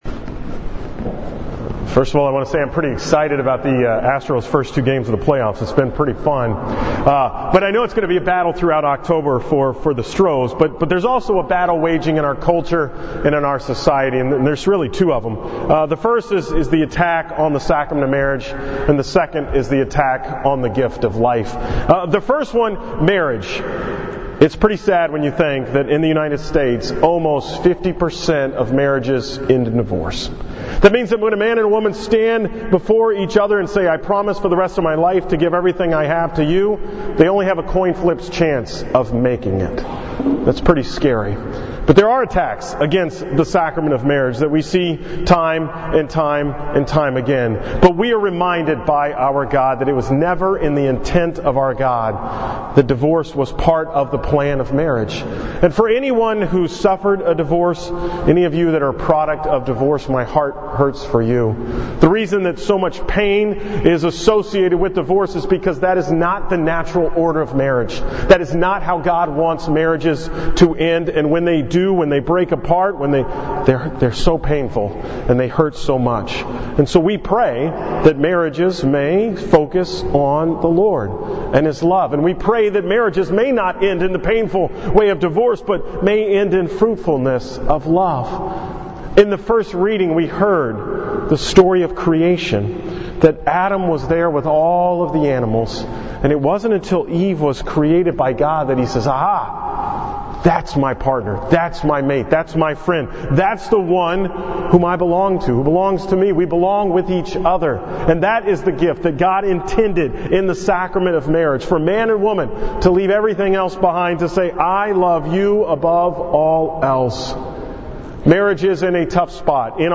From the 11 am Mass at St. Martha's on October 7, 2018